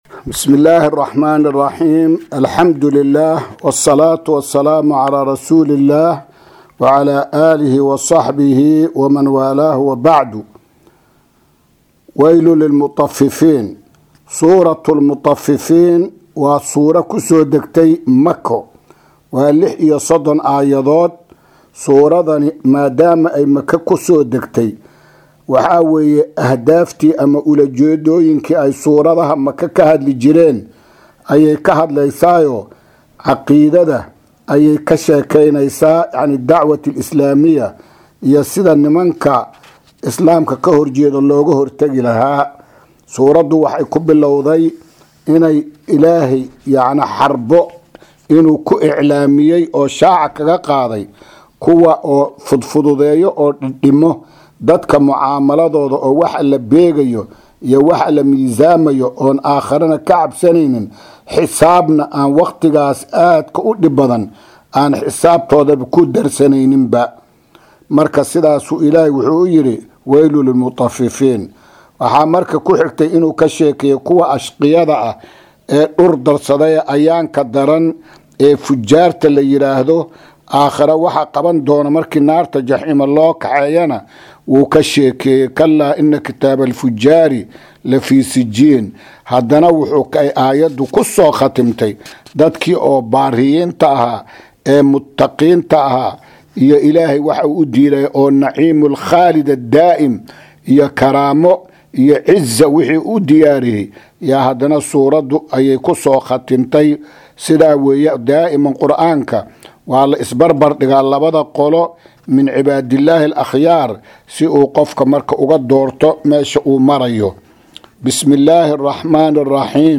Maqal:- Casharka Tafsiirka Qur’aanka Idaacadda Himilo “Darsiga 282aad”